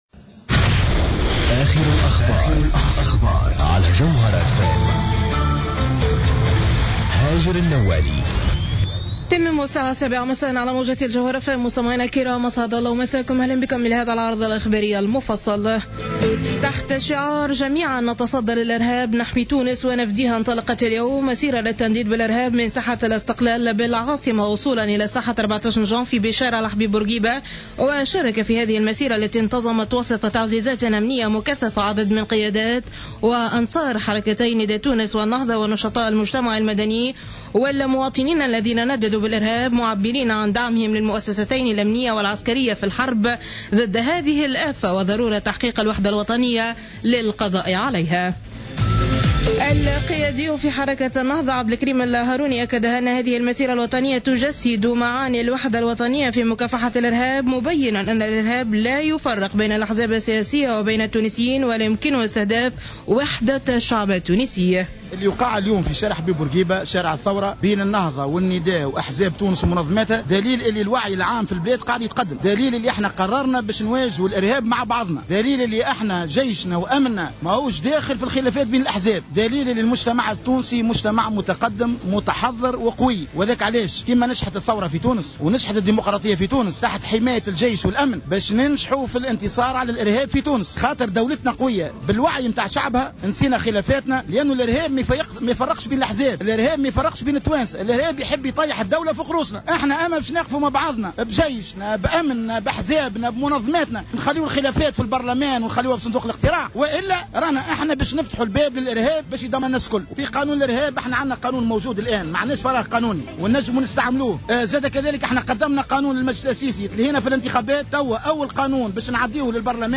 نشرة الأخبار السابعة مساء ليوم السبت 21 فيفري 2015